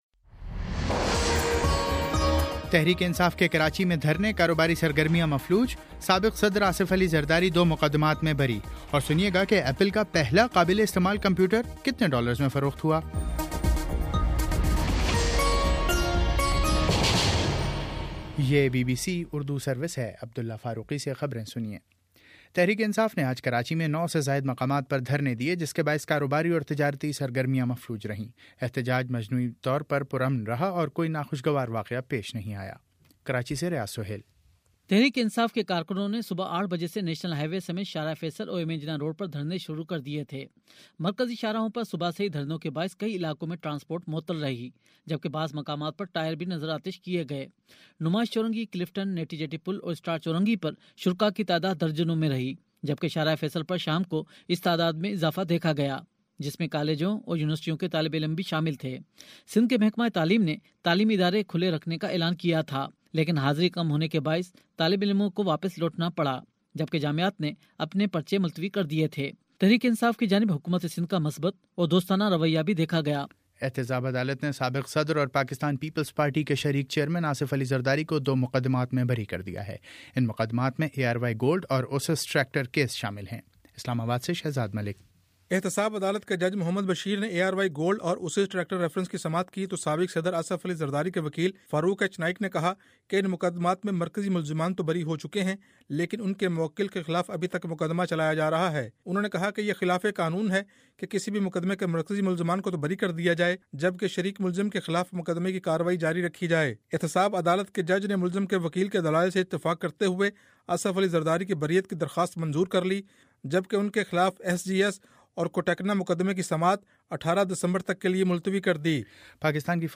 دسمبر 12 : شام سات بجے کا نیوز بُلیٹن